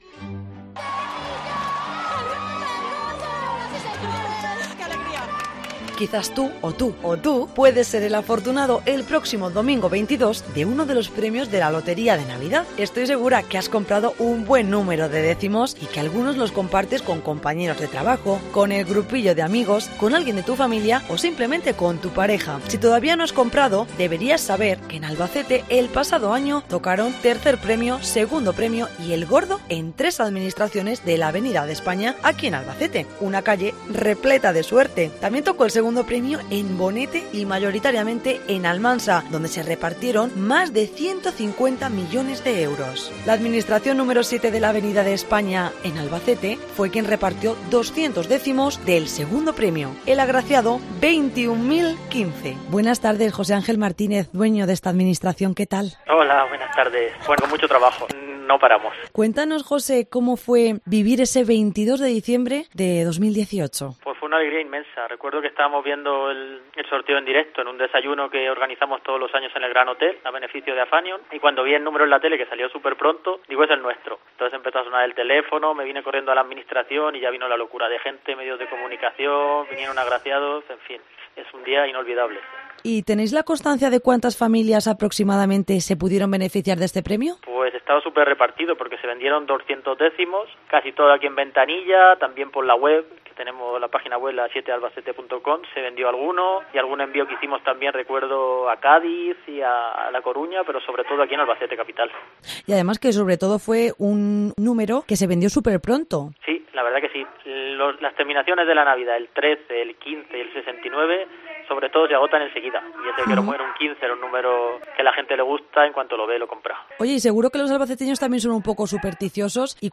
REPORTAJE | La lotería: la suerte de la Navidad